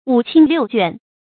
發音讀音
成語拼音 wǔ qīn liù juàn